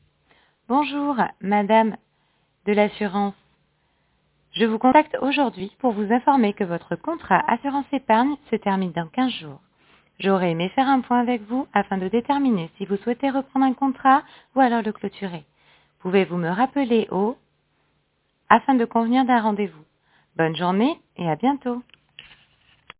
Un mailing vocal, aussi appelé SMS vocal, VMS ou encore Message répondeur, consiste à déposer en masse un message vocal directement sur le répondeur mobile de vos contacts.